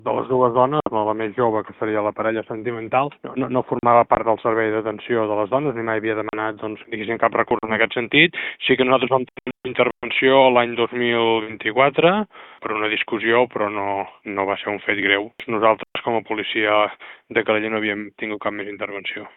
L’alcalde ha declarat que la filla no constava al circuit del servei municipal d’atenció a les dones víctimes de violència masclista, tot i que sí hi havia hagut una intervenció policial prèvia.